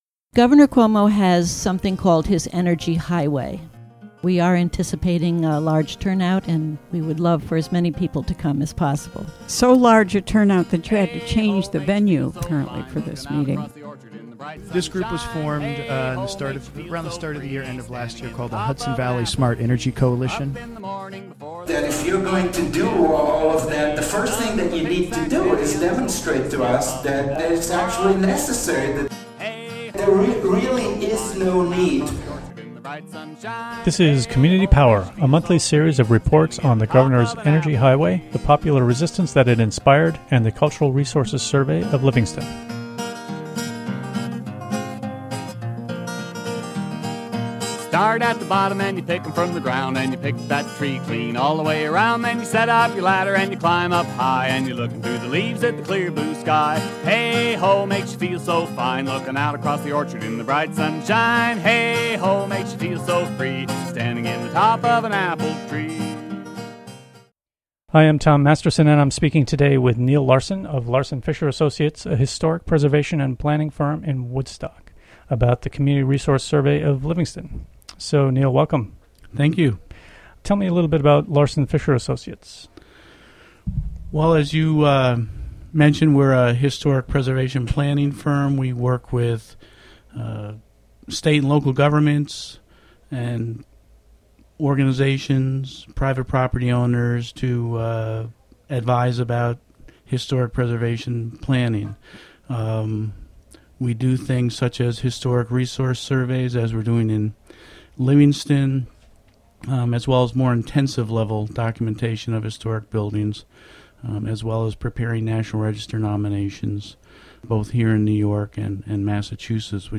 Recorded in the WGXC Hudson Studio, Fri., Sept. 4, 2015.